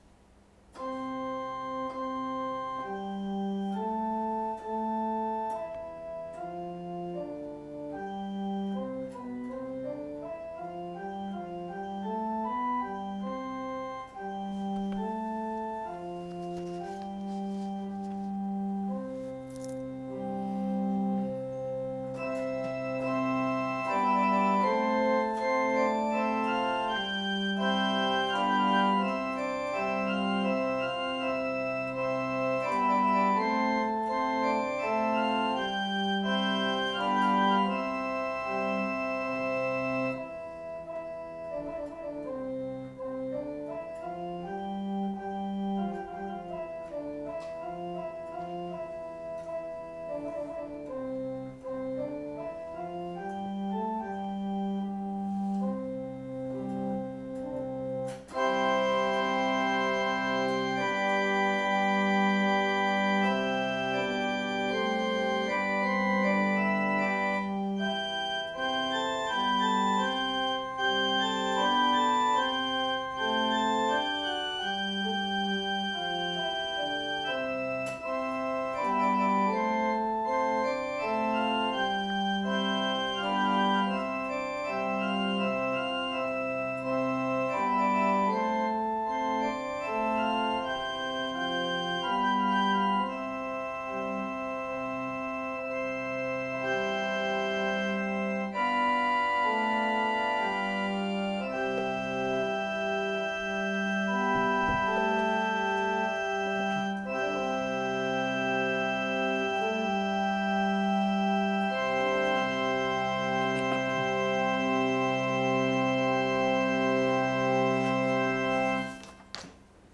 2025年11月23日朝の礼拝「わたしが命のパンである 나는 생명의 떡이니」せんげん台教会
説教アーカイブ。
音声ファイル 礼拝説教を録音した音声ファイルを公開しています。